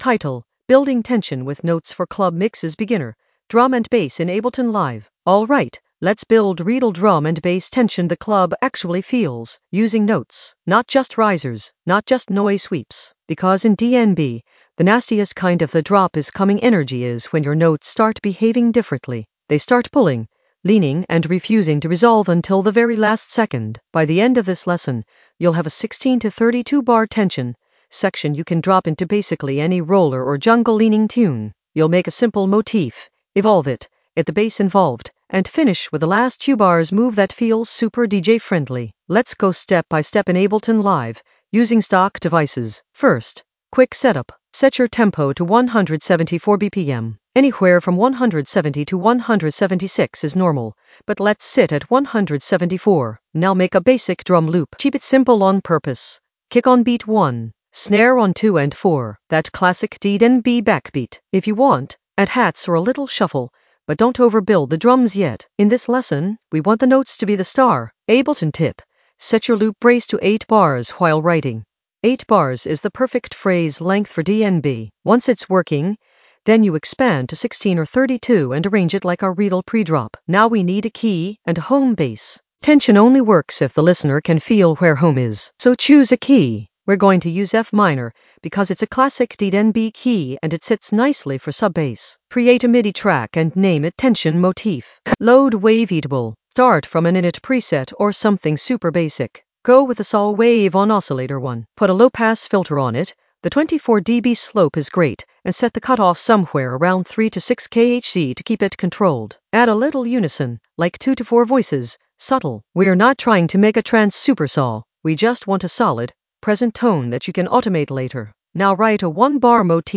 An AI-generated beginner Ableton lesson focused on Building tension with notes for club mixes in the Composition area of drum and bass production.
Narrated lesson audio
The voice track includes the tutorial plus extra teacher commentary.